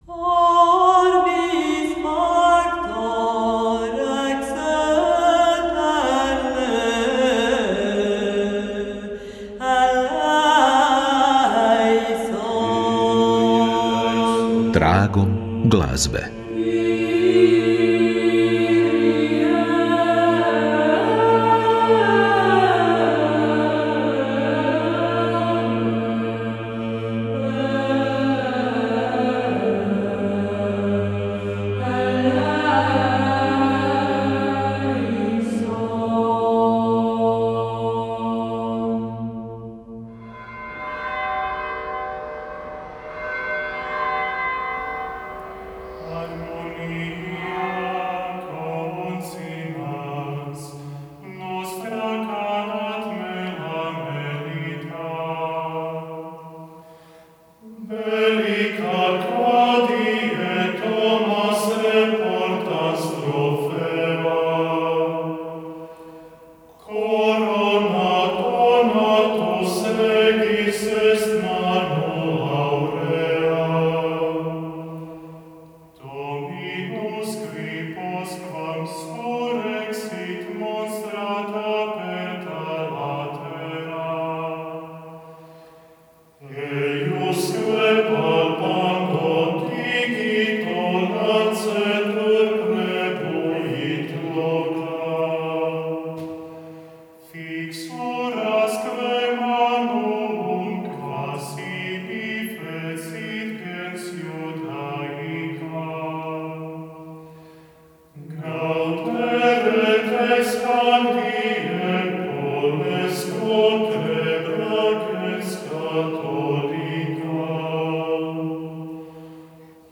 emisija_hrt_tragom_glazbe.wav